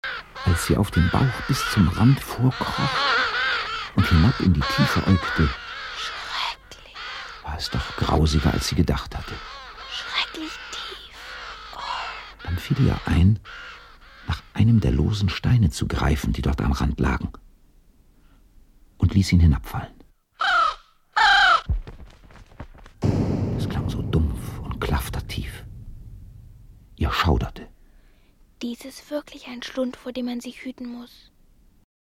Schlagworte Abenteuer • Bärenhöhle • Birk • Blitz • Familie • Fantasiewesen • Freundschaft • Glupafall • Hörbuch; Hörspiel für Kinder/Jugendliche • Hörspiel für Kinder/Jugendliche • Hörspiel für Kinder/Jugendliche (Audio-CD) • Jahreszeiten • Klassiker • Konflikt • Lovis • Märchen • Mattis • Pferde • Schweden • Skandinavien • Tonträger • Trolle • Vaterliebe